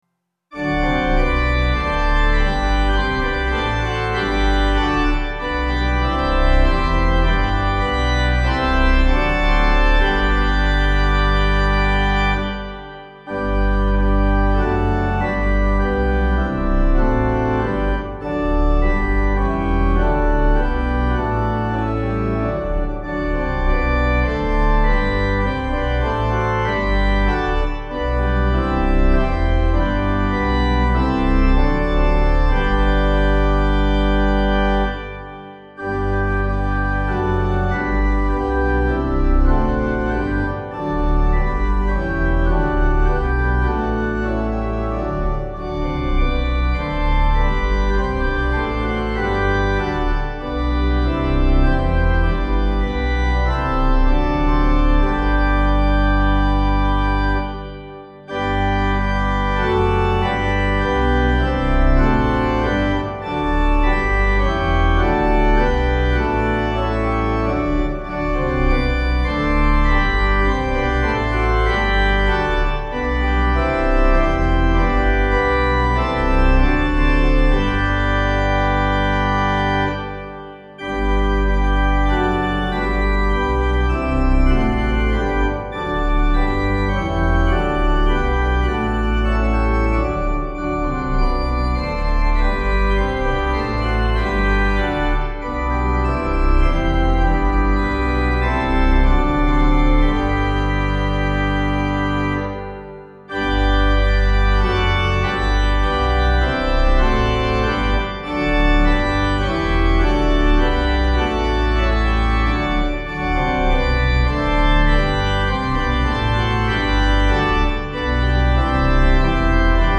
Meter: 8.8.8.8
Key: G Major